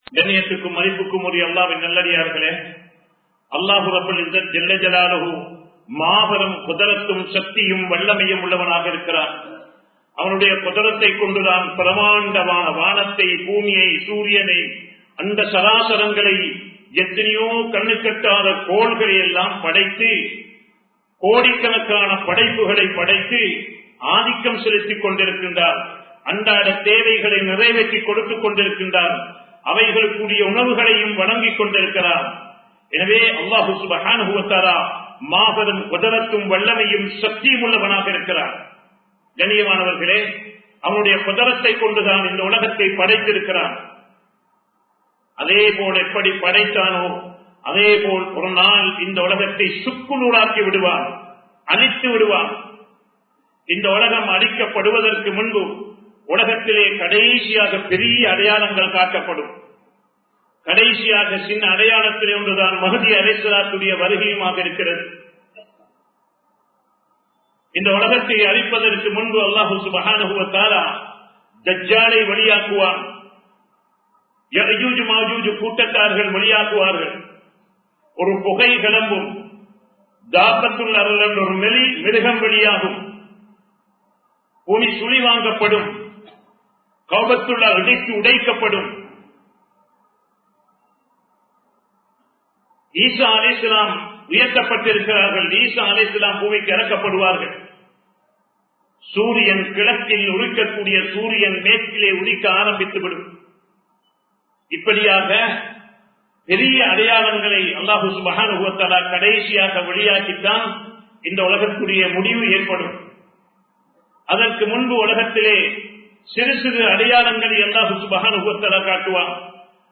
கியாமத் நாளின் அடையாளங்கள் | Audio Bayans | All Ceylon Muslim Youth Community | Addalaichenai
Samman Kottu Jumua Masjith (Red Masjith)